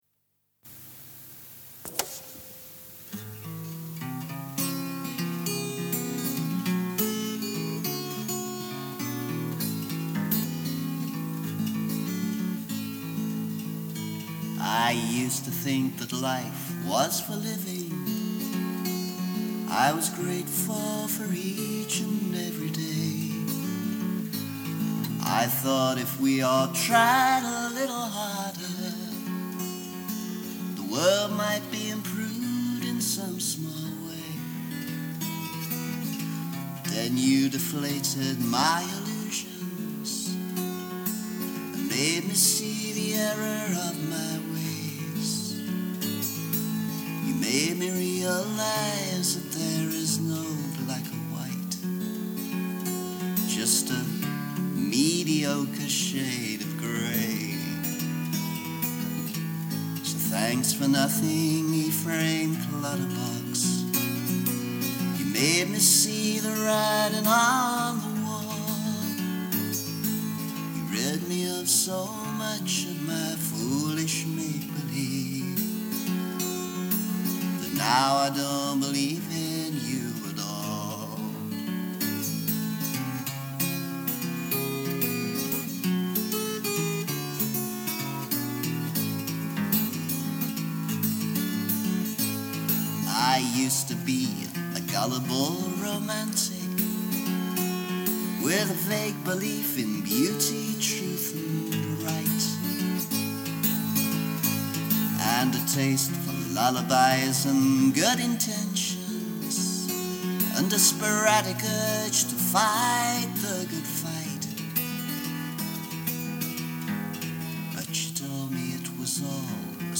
Which isn’t saying much, but at least it’s in a major key.
This recording was actually taken from a work/demo cassette I recorded in the 80s. Probably using a Fostex X-15 recorder and mixed down to my ghetto blaster. I’ve used it to replace the more recent demo recording that was originally here, as my voice was in better shape on this version.